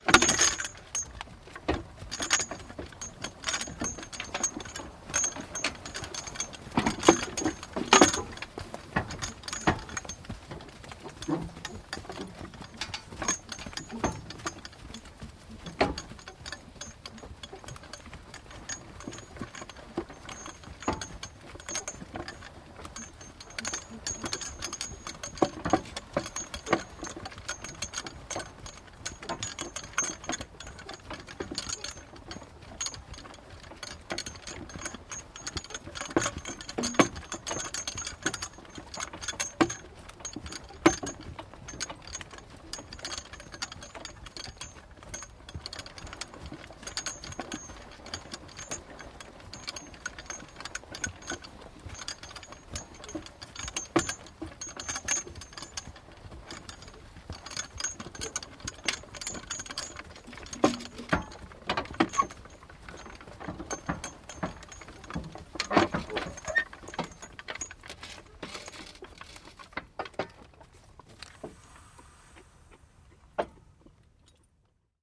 Погрузитесь в атмосферу прошлого с натуральными звуками повозки: скрип деревянных колес, цокот копыт лошадей, стук по неровной дороге.
Звук деревянной повозки с конской упряжью